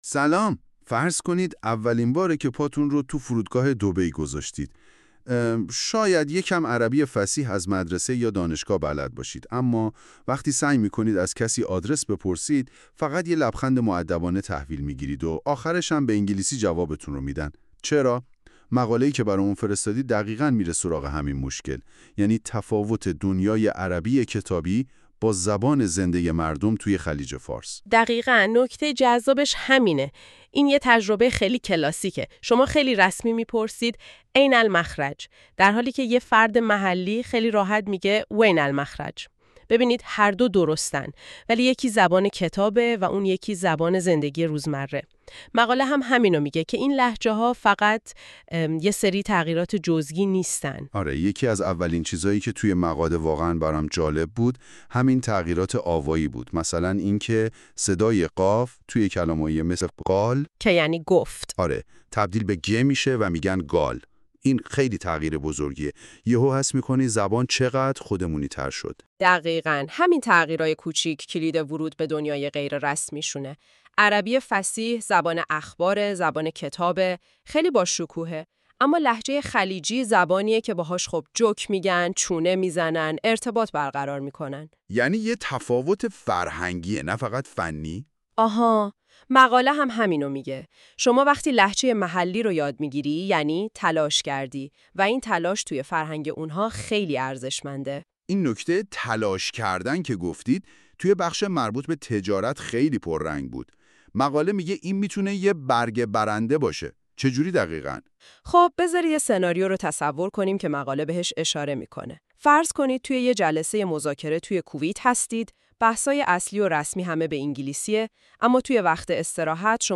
gulf-arabic-conversation.mp3